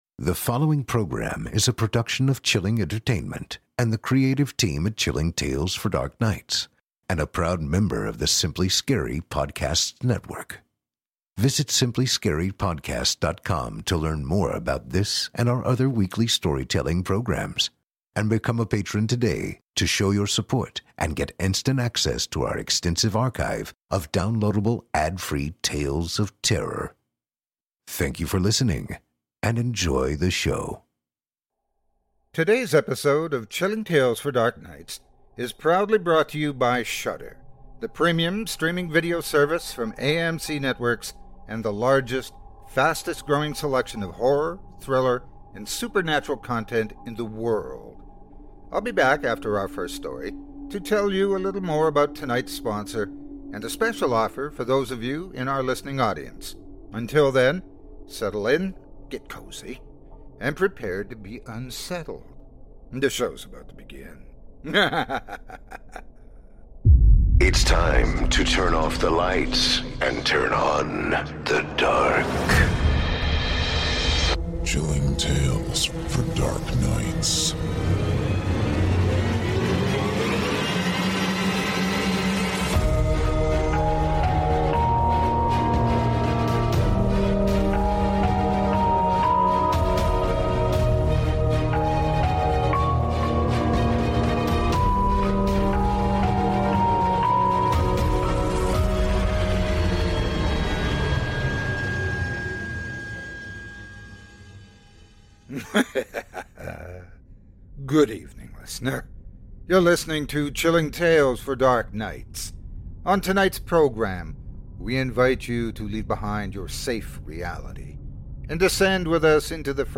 On tonight’s program, we invite you to leave behind your safe reality, and descend with us into the frightening depths of the most terrifying imaginations, with audio adaptations of three rounds of frightening fiction, about terrifying trips, living legends, and cantankerous canines.